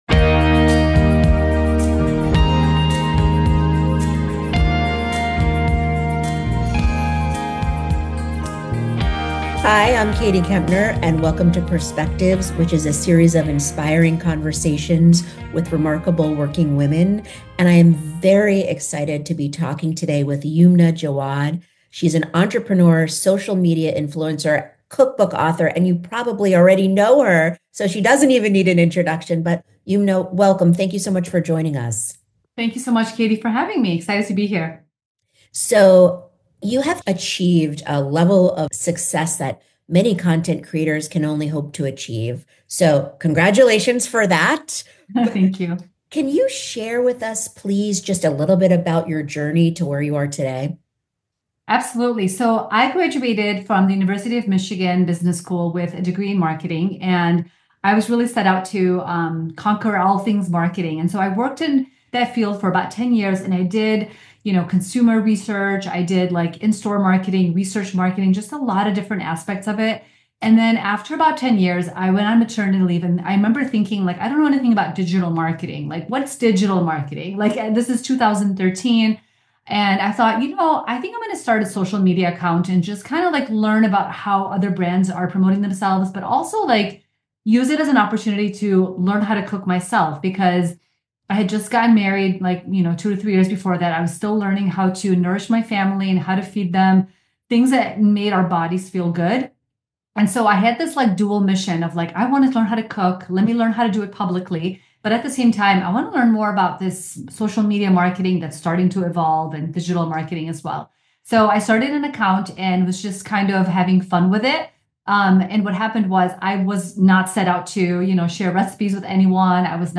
Influencer/Entrepreneur – Advertising Week